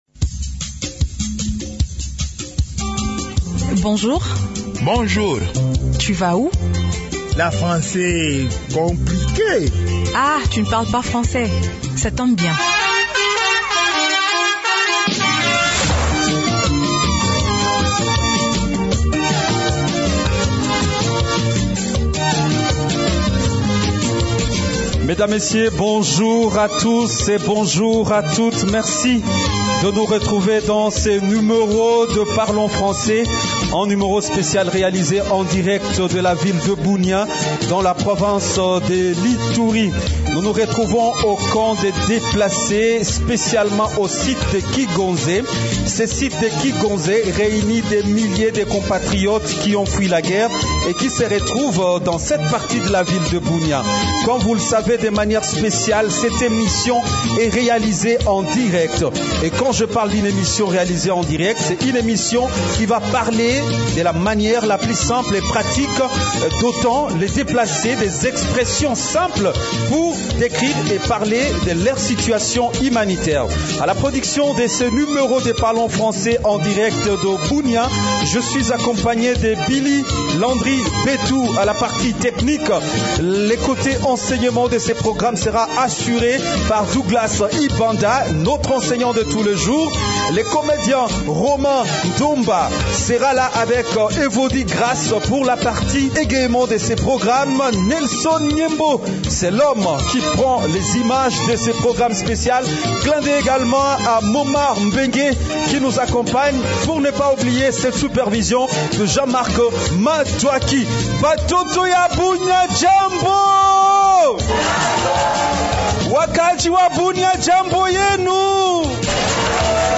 La réponse se trouve dans cette émission publique réalisée au camp de déplacés de Kigonze, à Bunia.